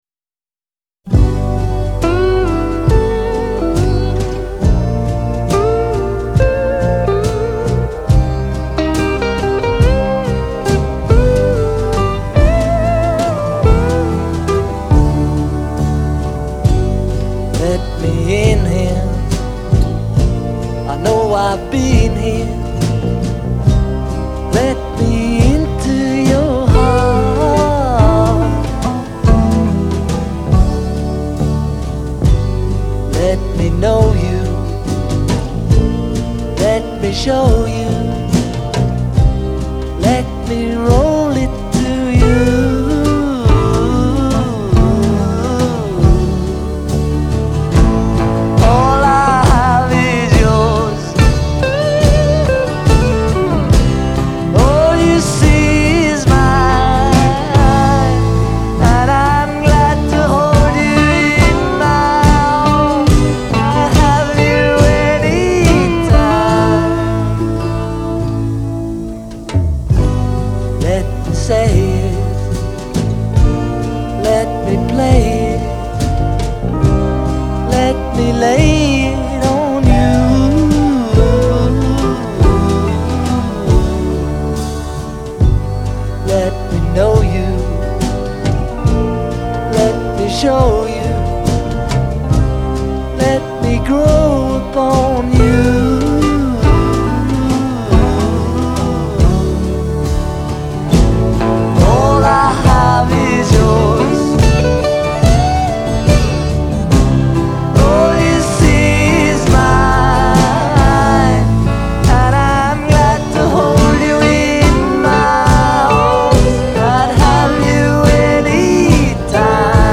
Genre : Rock, Folk